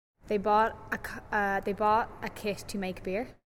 Note that after the initial trip-up the speaker regained her poise and by the end of the sentence had switched over to her present non-local accent and pronounced the last word beer with a final /-r/ and had a slight high-rising terminal.
Switch-over accent with hesitation (open BOUGHT vowel and final T deletion), same speaker as in previous sound file
SO_BOUGHT_BEER_(with_hesitation).mp3